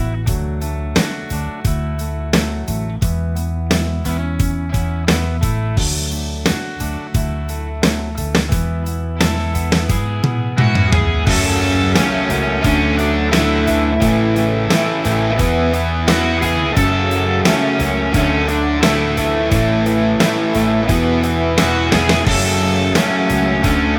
Minus Main Guitar Pop (1990s) 4:21 Buy £1.50